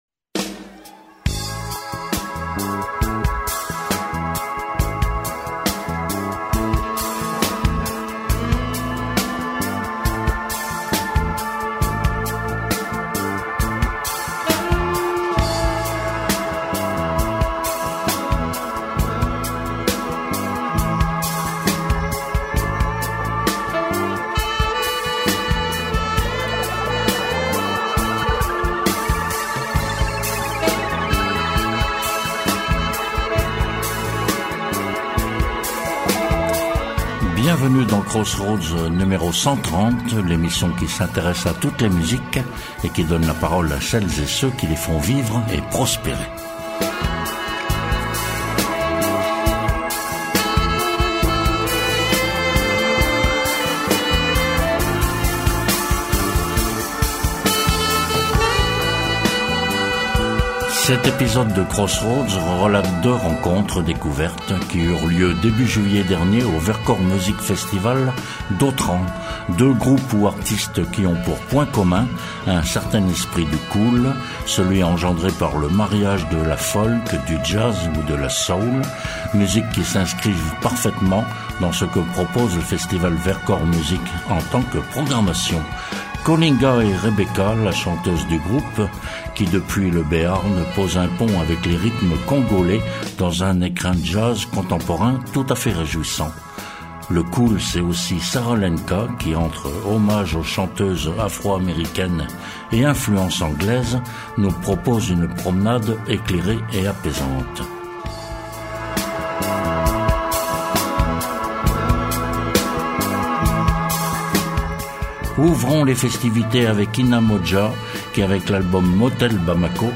En complément de programmation, divers artistes à l’unisson de cet esprit cool, si agréable, à portée d’émotions.